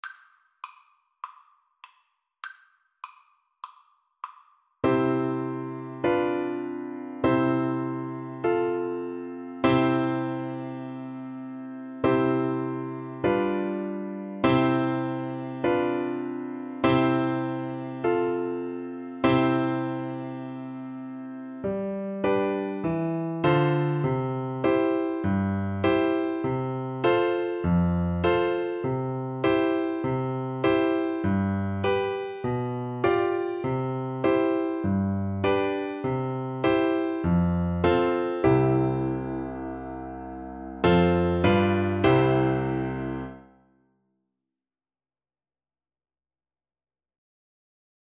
Traditional Music of unknown author.
C major (Sounding Pitch) (View more C major Music for Voice )
4/4 (View more 4/4 Music)
Moderato